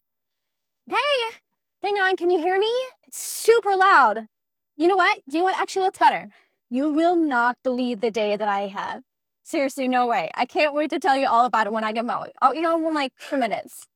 "description": "speech",